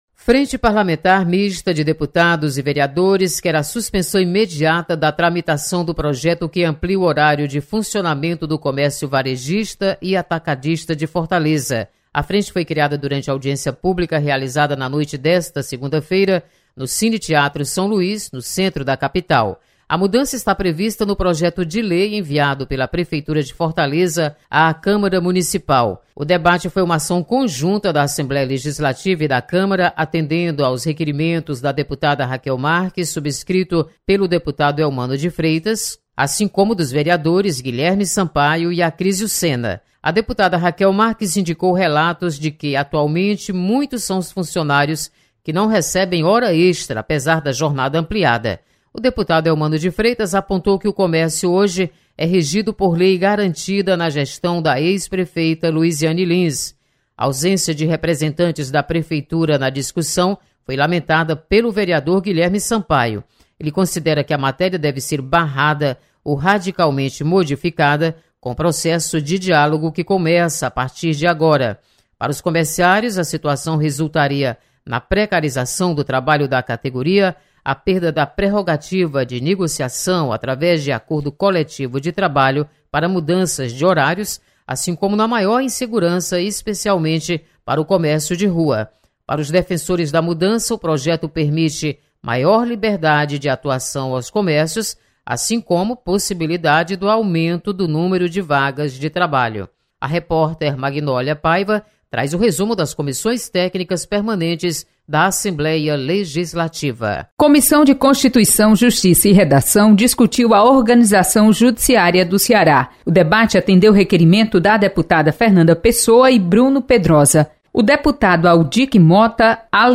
Ampliação do horário de funcionamento do comércio em Fortaleza é discutida por deputados e vereadores. Repórter